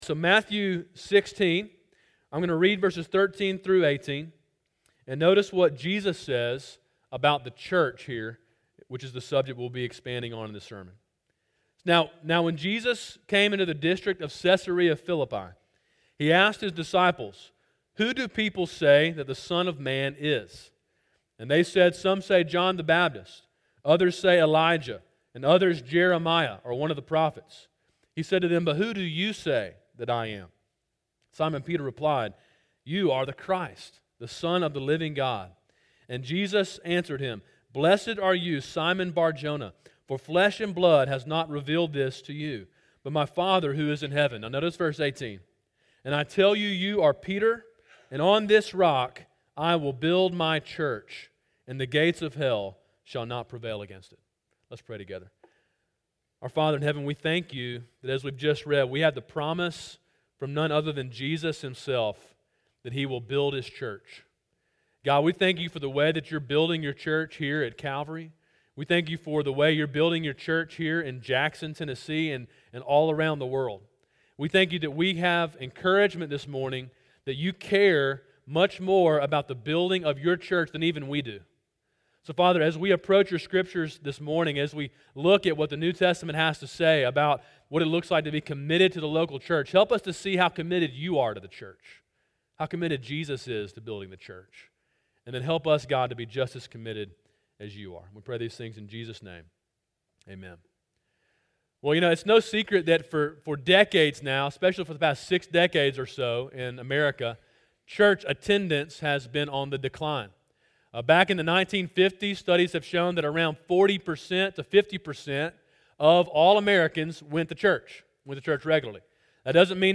sermon2-11-18.mp3